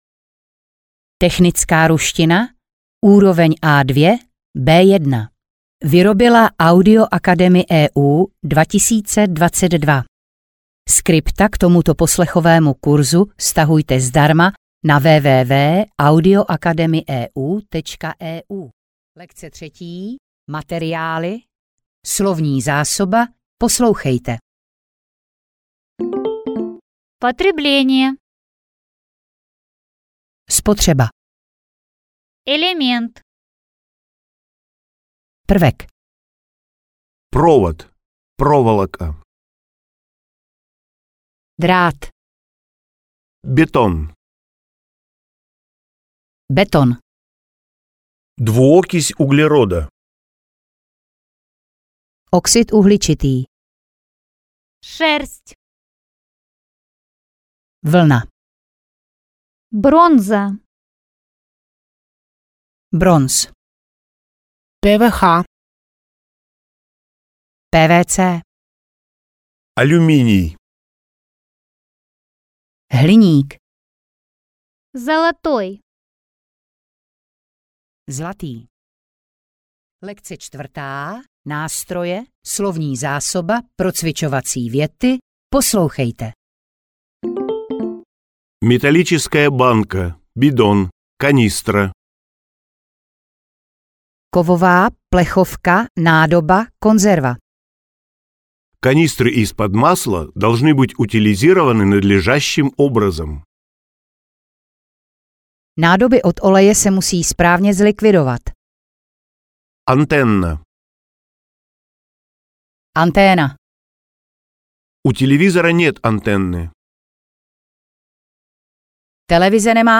Technická ruština A1-B1 audiokniha
Ukázka z knihy
Dále máte k dispozici slovíčko následované příkladovou větou, opět v obou variantách překladu (stopa 3 a 4).
Jakmile budete zvládat překládat věty z ruštiny do češtiny (lekce 6) v časové pauze před českým překladem, tak jste vyhráli.